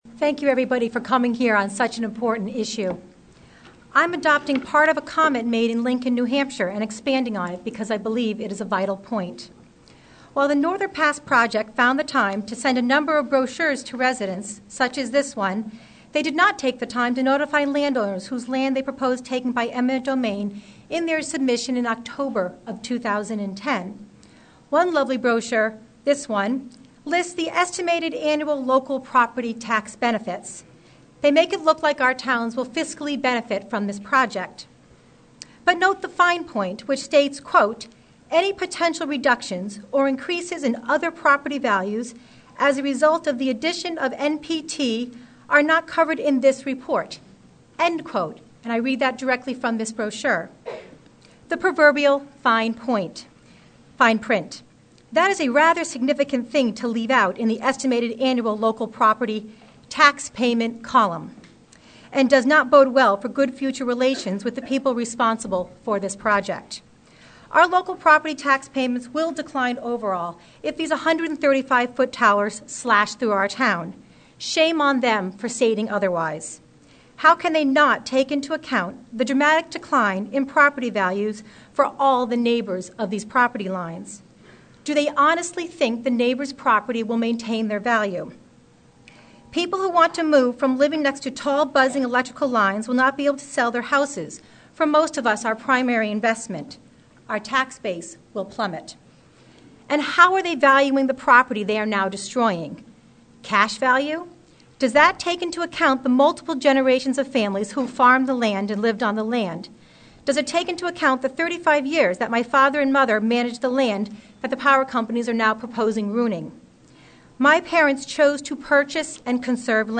Public Scoping Meeting- Haverhill 3/20/11: